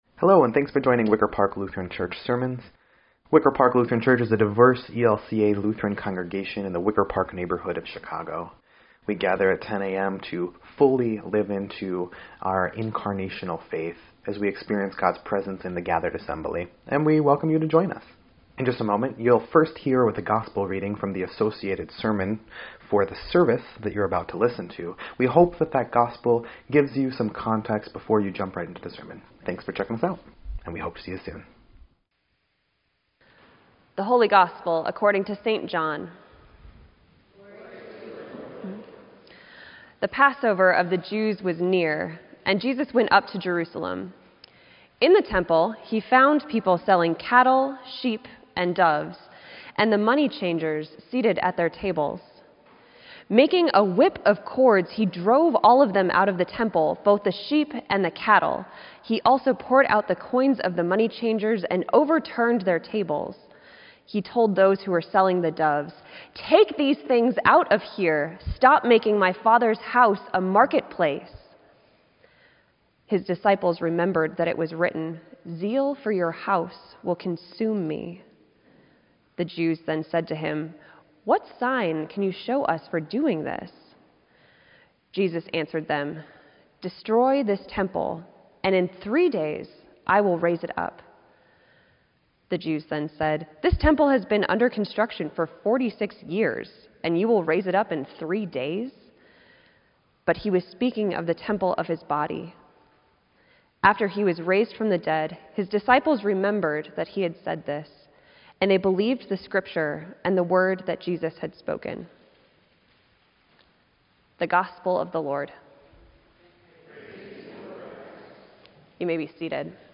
Sermon_3_4_18_EDIT.mp3